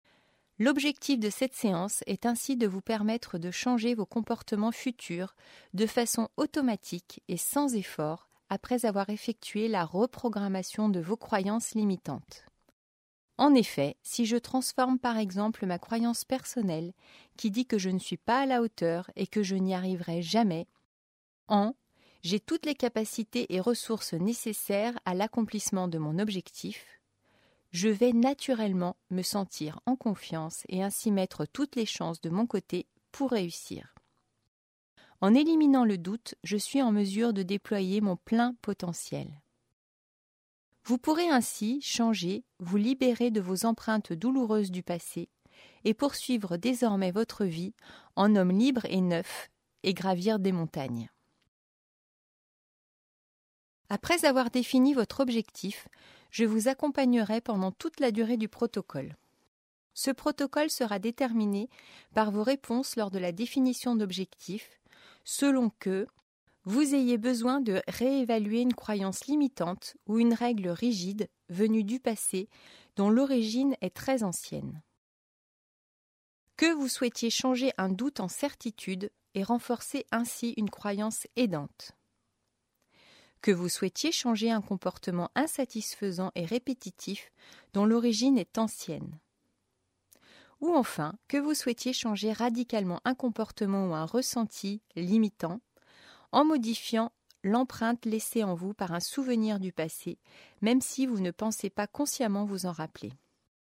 01/09/2014 Ce livre audio, séance de Coaching en PNL (Programmation Neuro Linguistique), propose plusieurs protocoles d’accompagnement afin de permettre à l’auditeur de remplacer ses croyances limitantes et reprogrammer son histoire favorablement.